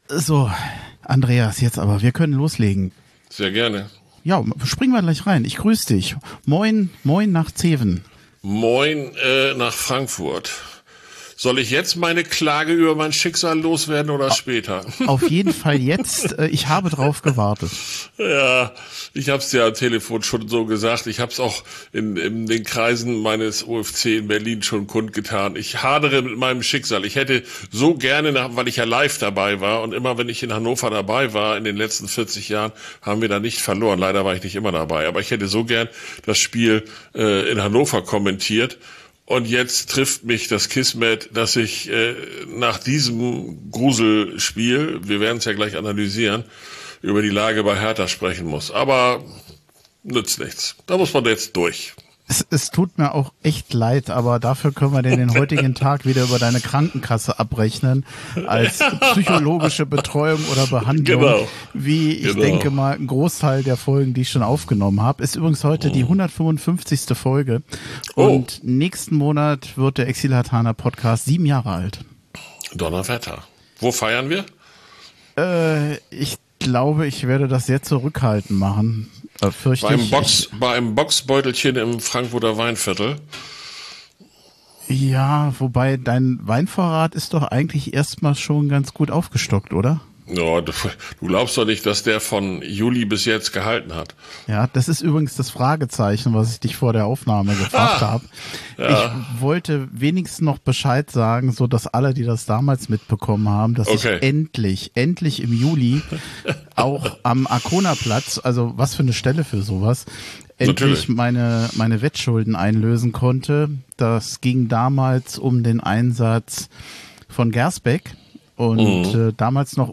Keine einfache Aufnahme gewesen unter diesen Umständen, aber wir haben das Beste daraus gemacht.